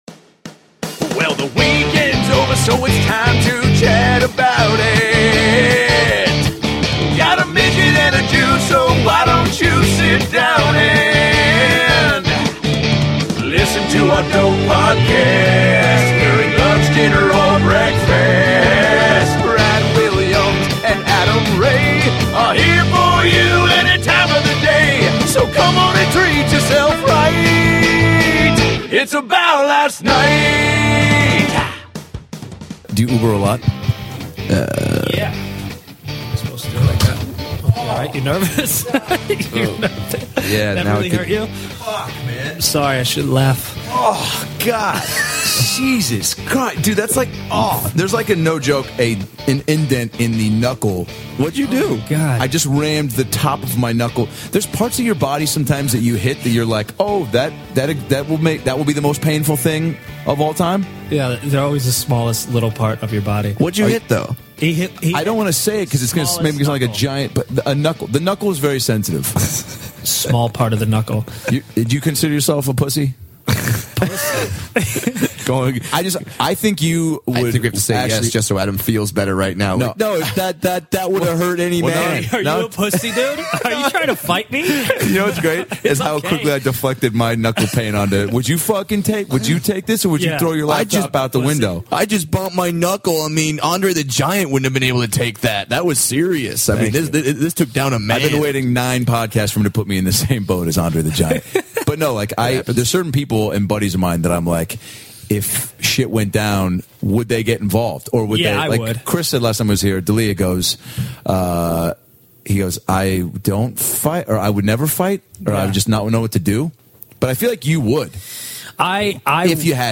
The hilarious Brent Morin stops by to talk about making a roller-skating movie, his new NBC show "Undateable," and serenades us with 90's pop ballads.